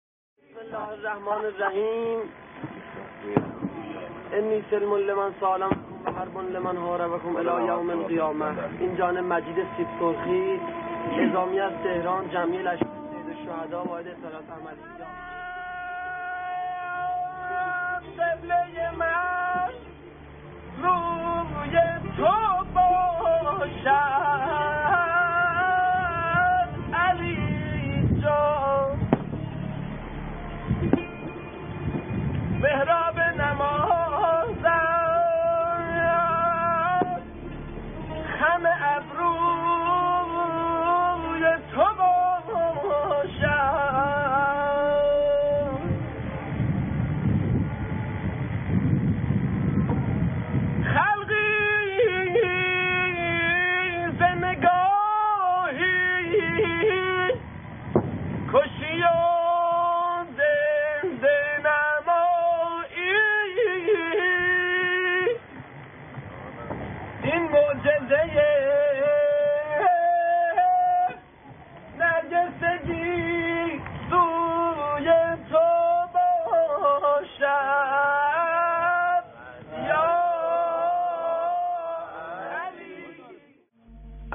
مداحی
در جبهه